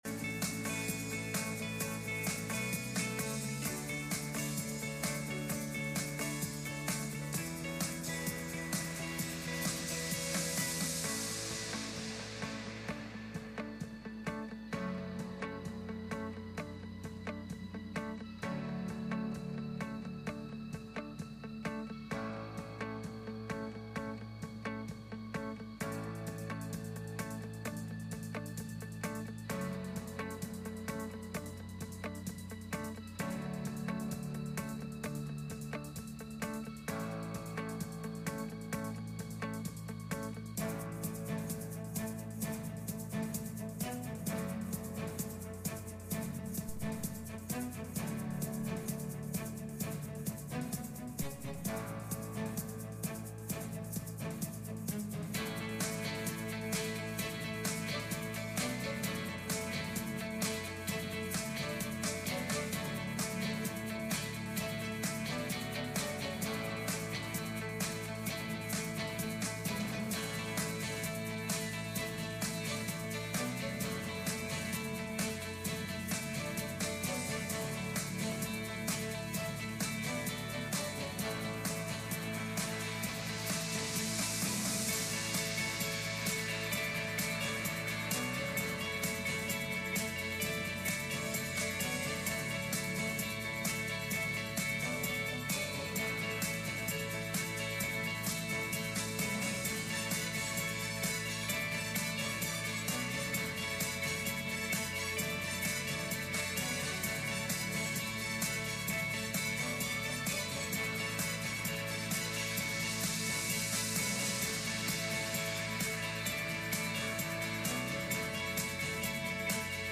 Passage: Philippians 4:2-3 Service Type: Midweek Meeting